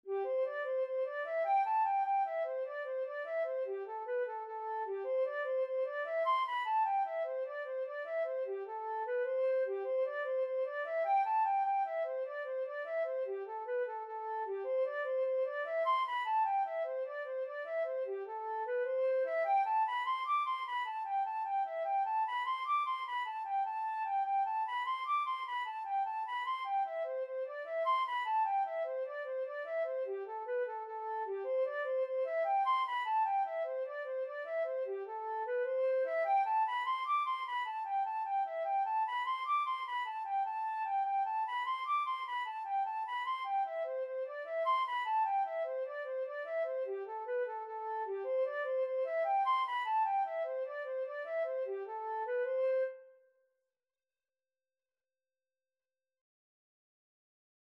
Flute version
6/8 (View more 6/8 Music)
Flute  (View more Intermediate Flute Music)
Traditional (View more Traditional Flute Music)